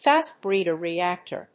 /fæstbriːdər(米国英語)/
アクセントfást bréeder reàctor